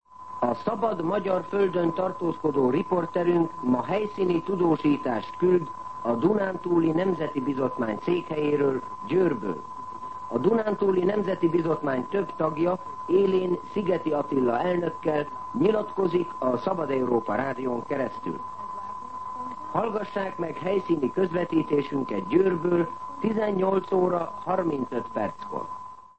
Műsorajánló